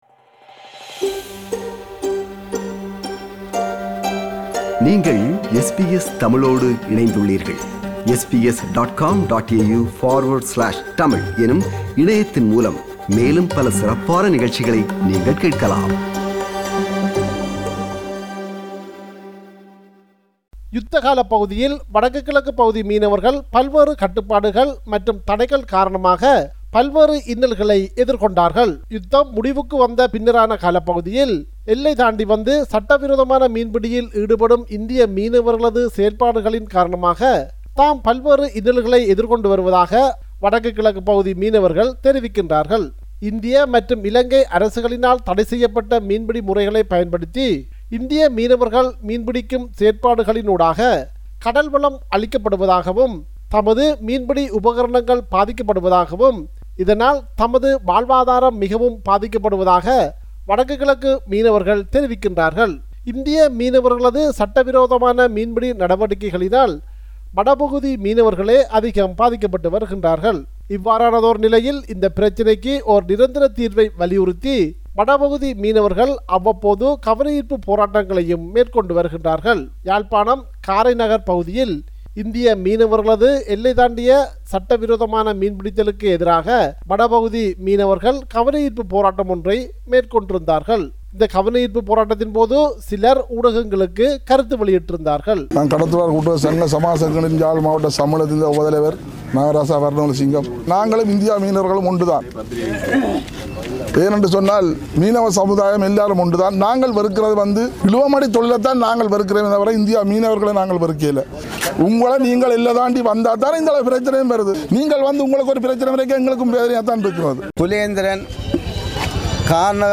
our correspondent in Sri Lanka